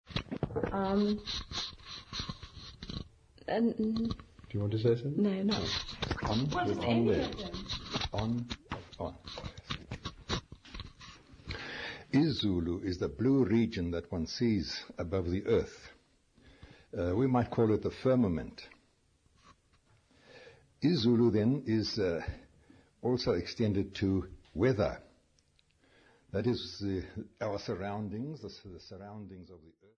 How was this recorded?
Africa South Africa Zwelitsha, Eastern Cape sa field recordings